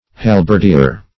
Halberdier \Hal`berd*ier"\ (h[o^]`b[~e]rd*[=e]r")